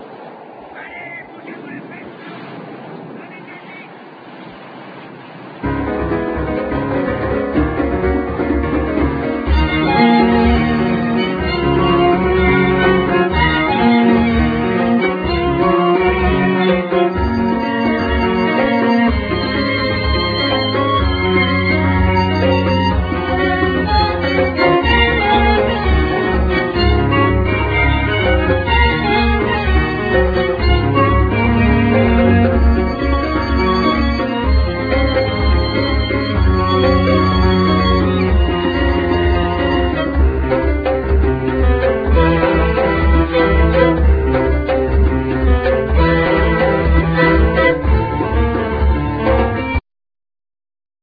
Bandneon
Trombone
Baryton saxophone
Contrabass
Tabla
Cello